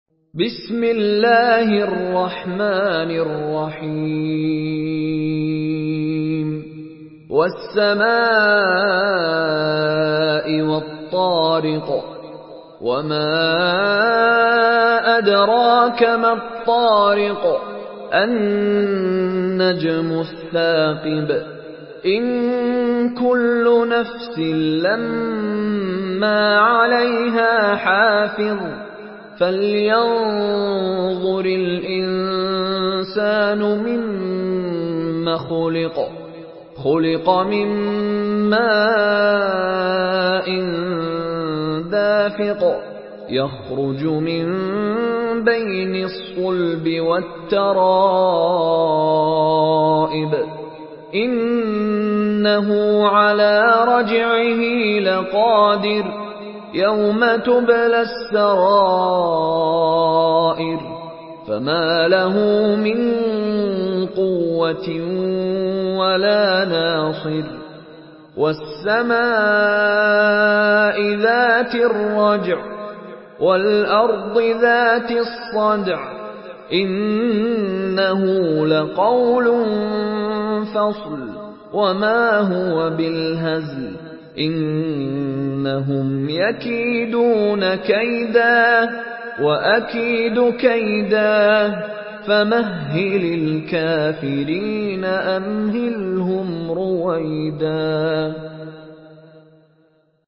Surah الطارق MP3 in the Voice of مشاري راشد العفاسي in حفص Narration
Surah الطارق MP3 by مشاري راشد العفاسي in حفص عن عاصم narration.
مرتل